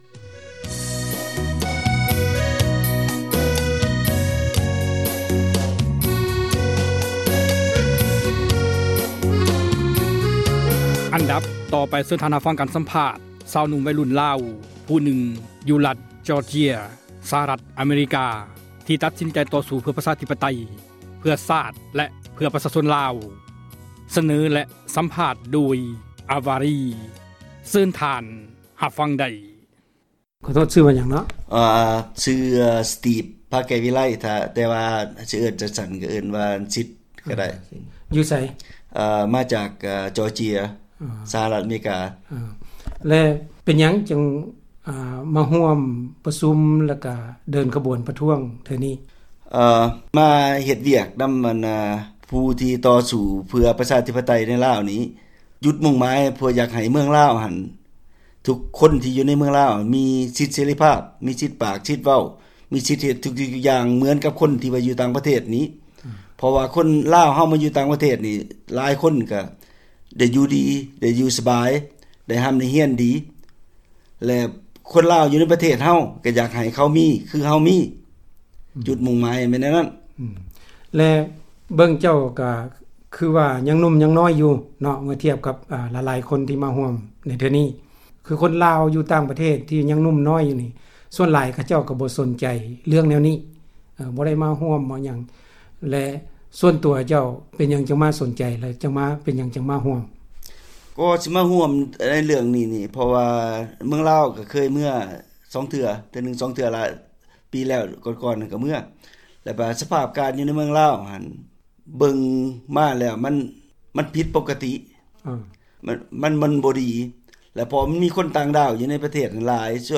F-default2 ການສຳພາດ ຊາວໜຸ່ມໄວລຸ້ນ ຈາກ Georgia ສະຫະຣັຖ ອະເມຮິກາ ທີ່ຕໍ່ສູ້ເພື່ອ ປະຊາທິປະໄຕ ໃນລາວ